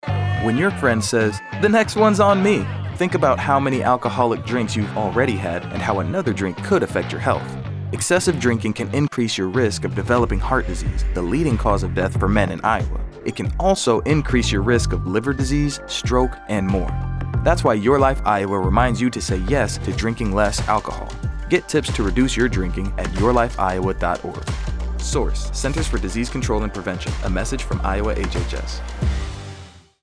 :30 Radio Spot | Male
Radio spot :30 Radio Spot | Male This prevention campaign educates Iowans, ages 45 and over, about excessive — or heavy and binge — drinking.